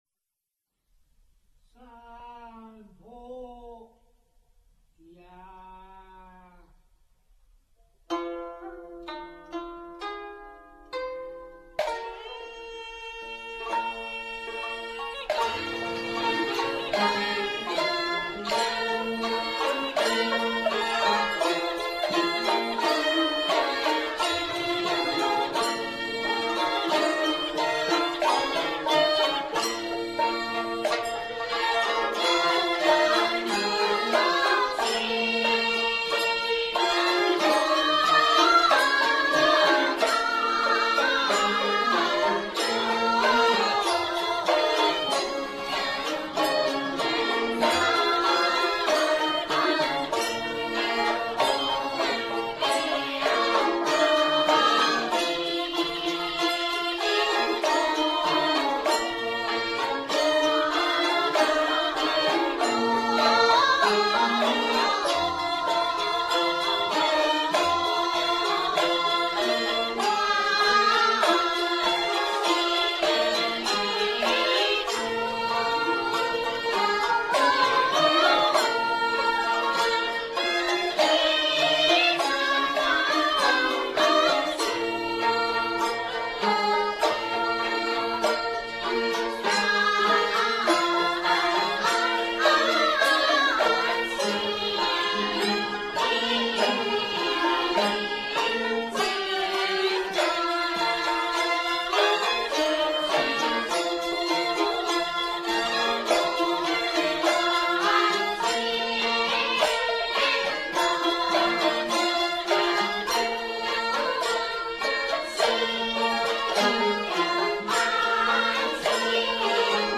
纳西古乐《一江风·曲牌》等